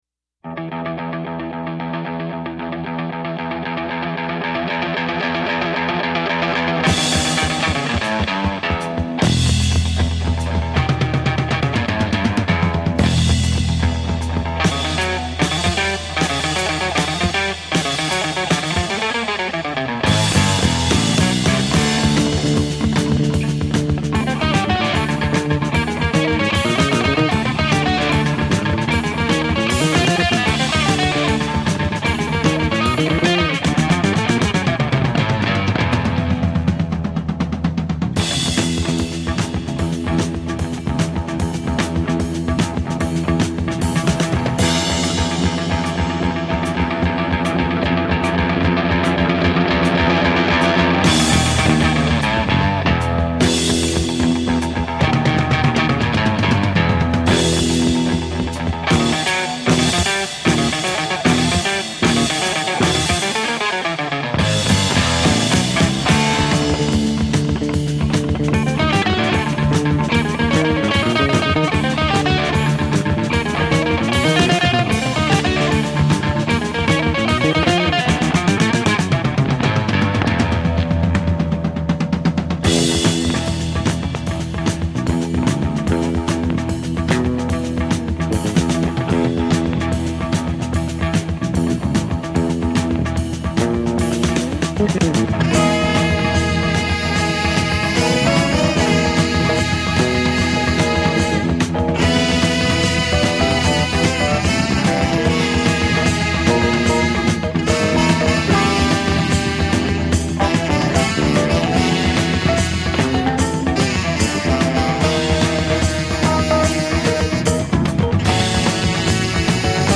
a UK prog-jazz-fusion band